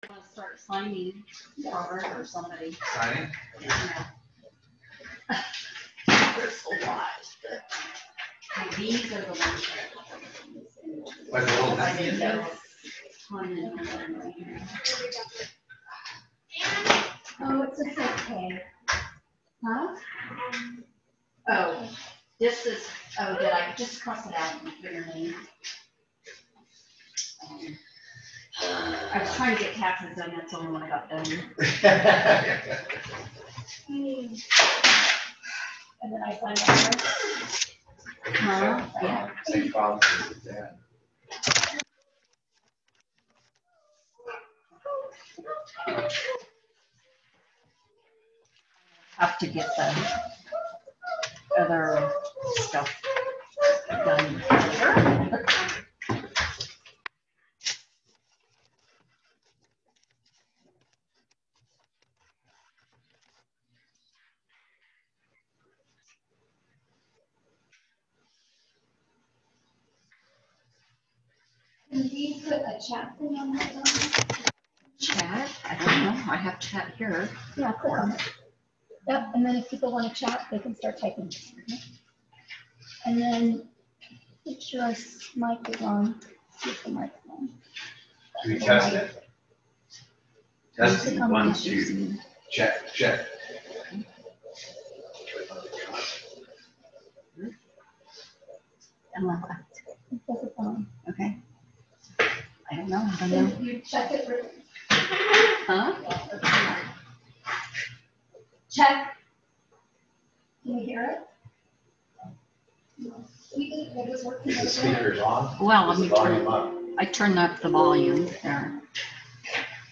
The Board meets the 2nd Monday of each month at 6:00 p.m. at the Stones Landing Volunteer Fire Department building located at 509-695 Stone Road.
Board Meeting